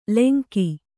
♪ lenki